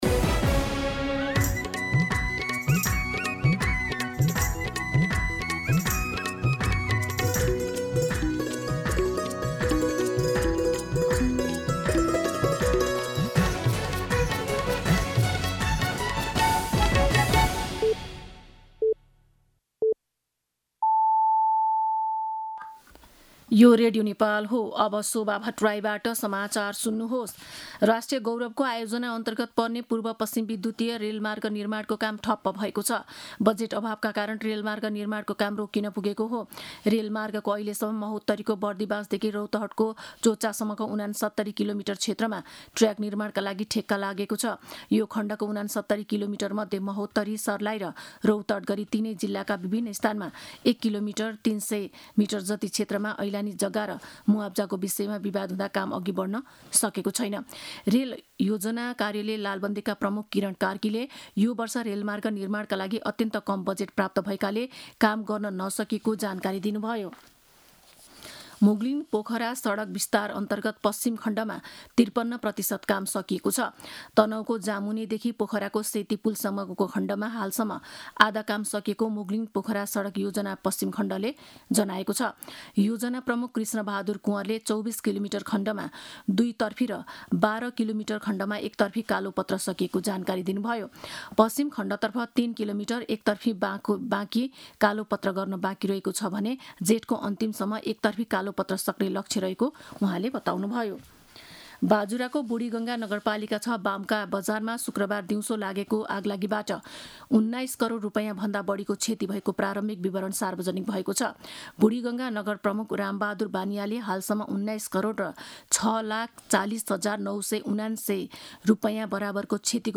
मध्यान्ह १२ बजेको नेपाली समाचार : २६ जेठ , २०८२